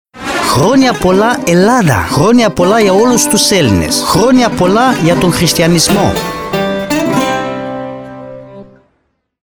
De Ziua Națională a Greciei, am provocat vorbitorii nativi de limbă greacă să ne ofere un curs rapid.
Pentru că Ziua Greciei este strâns legată de Buna Vestire, data de 25 martie este prilej pentru o dublă sărbătoare, așa că la Europa FM, unul dintre jingle-uri se traduce astfel: ”La mulți ani, Grecia! La mulți ani, tuturor grecilor! La mulți ani, creștinismului!”